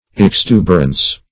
Search Result for " extuberance" : The Collaborative International Dictionary of English v.0.48: Extuberance \Ex*tu"ber*ance\, n. A swelling or rising; protuberance.